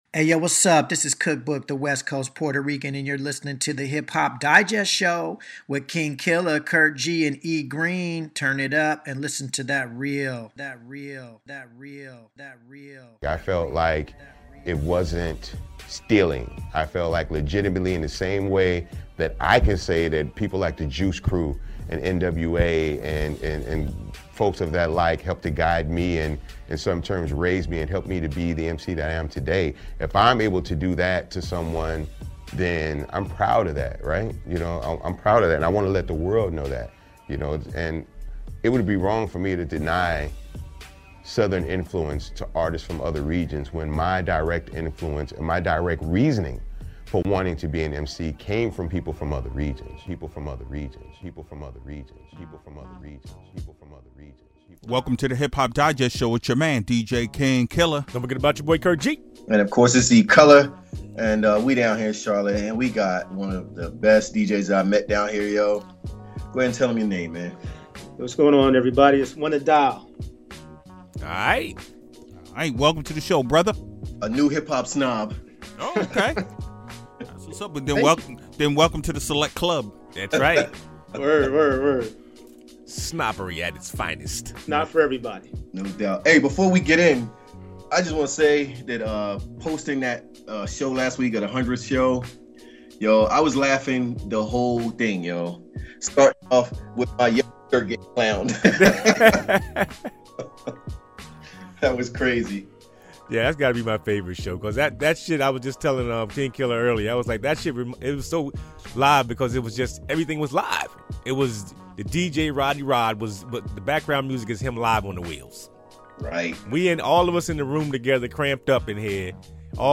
One of Charlotte's finest DJ's joined us!